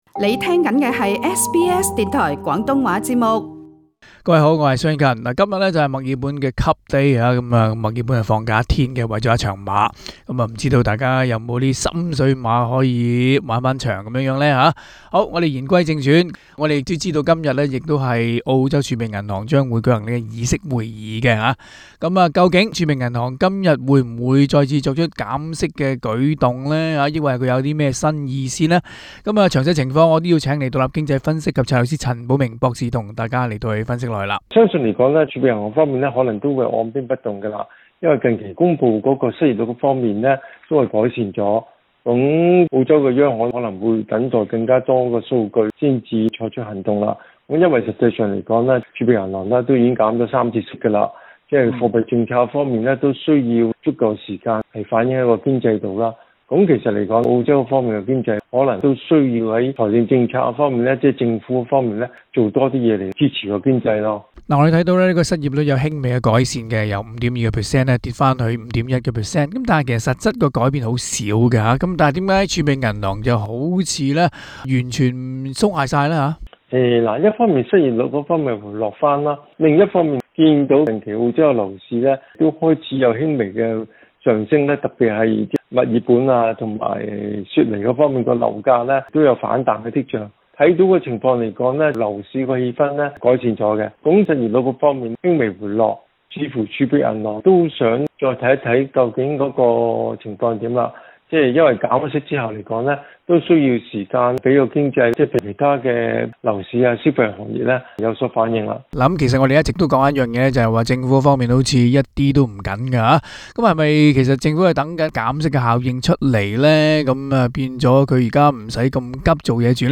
AAP Source: AAP SBS廣東話節目 View Podcast Series Follow and Subscribe Apple Podcasts YouTube Spotify Download (15.53MB) Download the SBS Audio app Available on iOS and Android 今天是墨爾本杯賽日，也是澳洲儲備銀行舉行議息會議的日子。